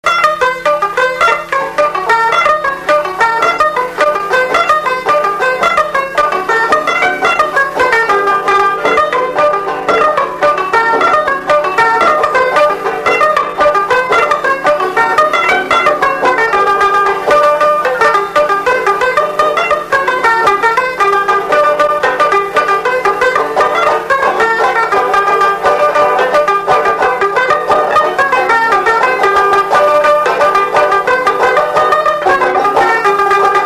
Saint-Leu
Instrumental
danse : séga
Pièce musicale inédite